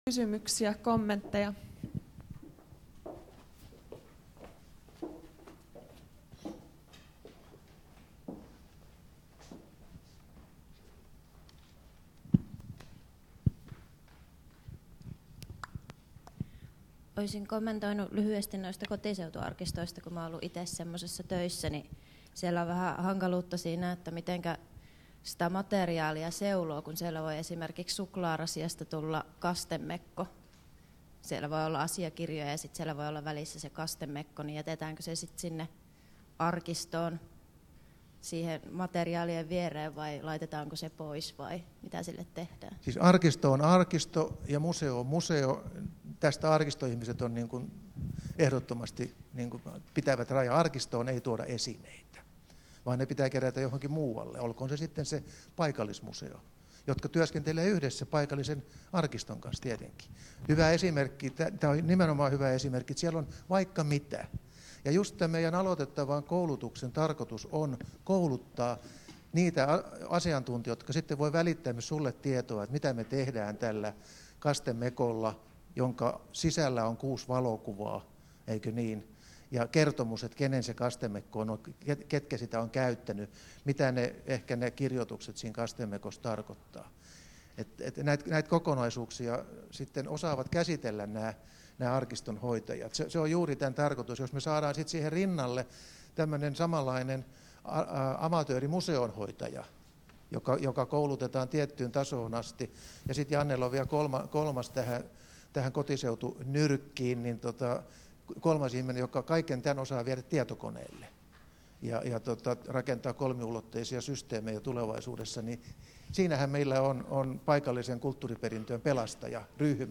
Keskustelu